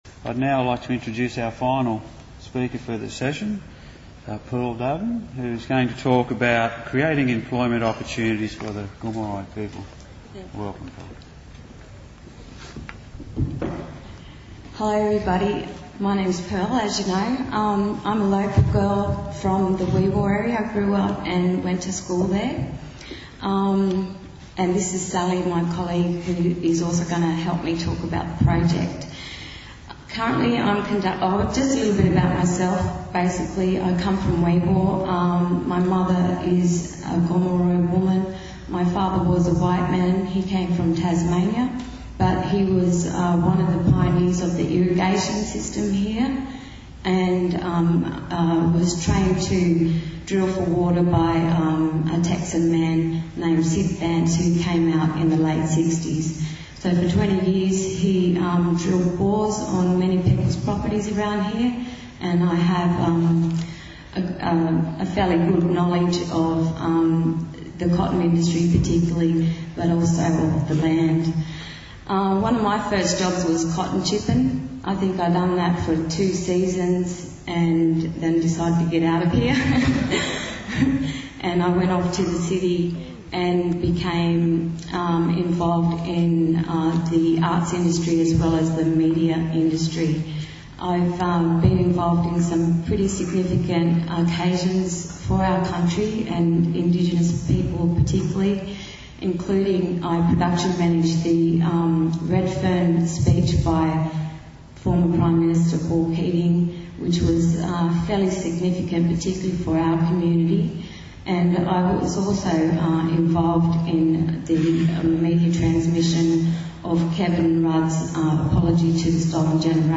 Creating employment opportunities for Gomeroi people.Sustaining Rual comminities conference Audio presentation 2012 | Inside Cotton